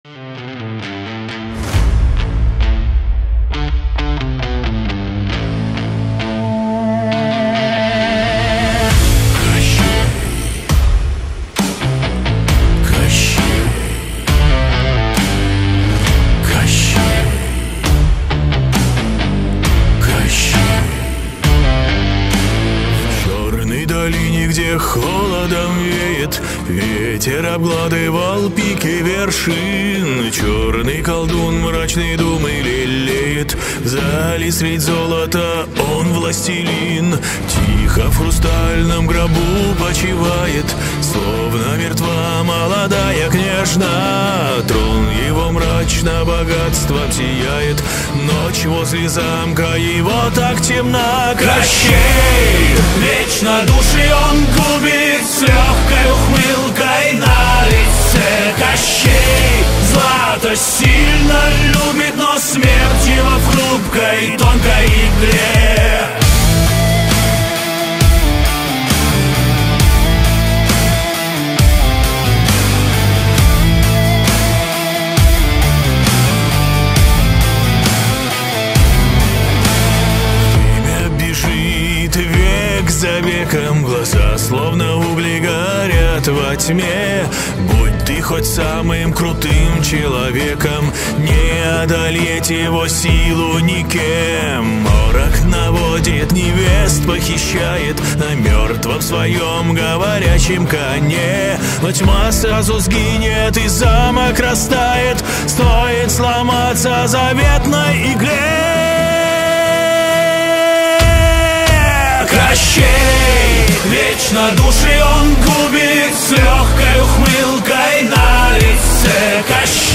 Рок сказ